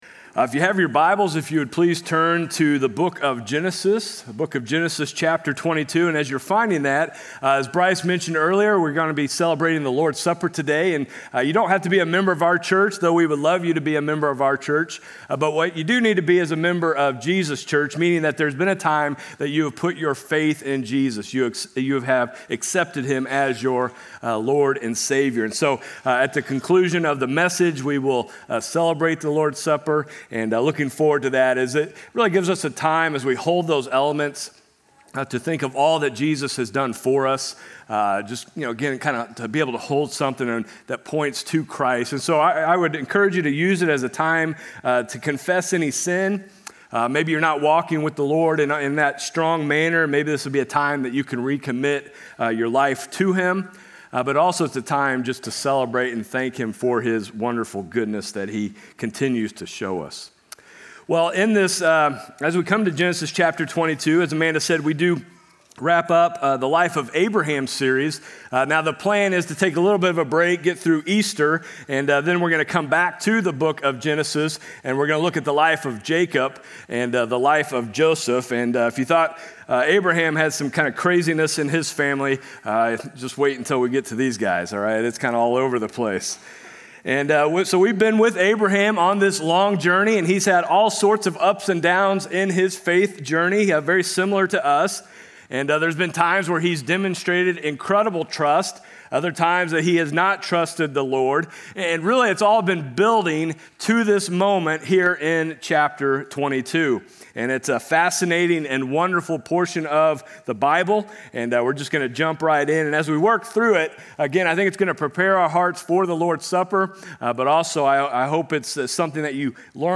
Sermons | FBC Platte City